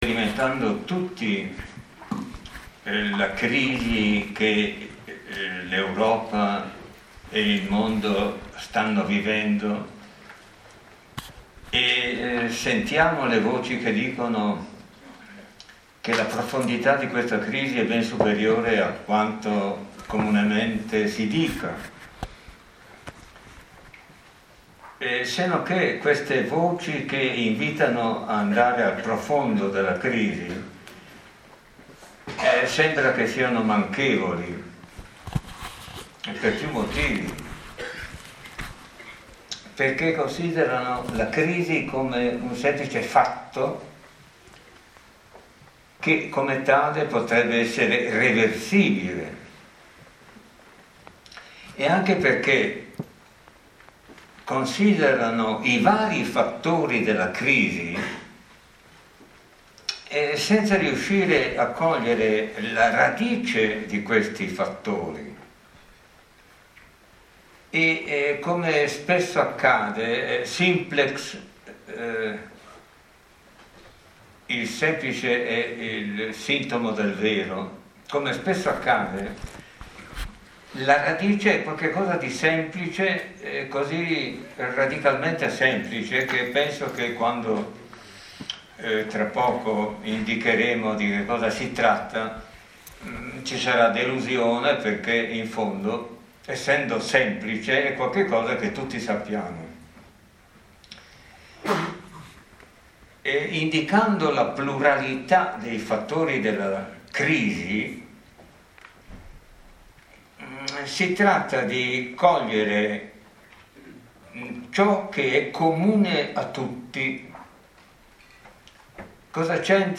EMANUELE SEVERINO pensa GIACOMO LEOPARDI, al Teatro Franco Parenti di MILANO, in: LAVIA dice Leopardi, SEVERINO lo pensa |domenica 25 marzo 2018, ore 19,30-20,30.
AUDIO della lezione di Emanuele Severino, 19,30-20,30: